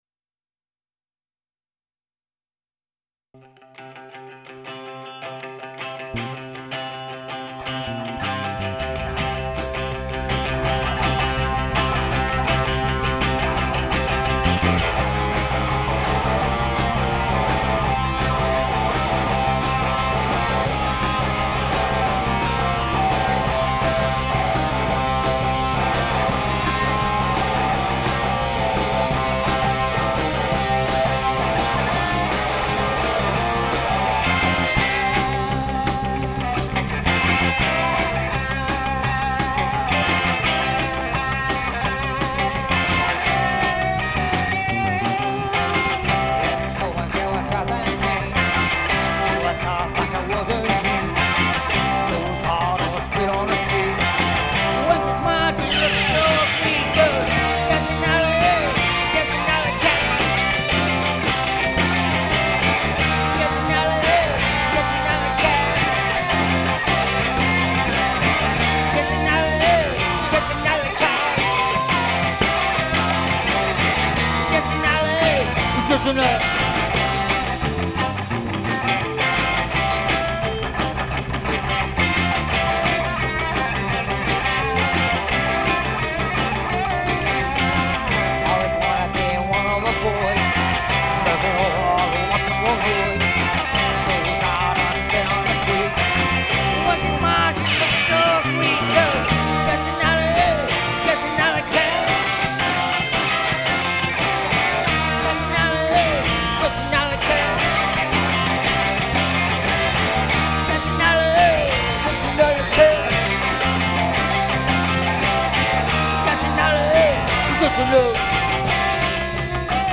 guitar/vocal
Bass
Jamming rendition at the Jungle Studio Feb 2013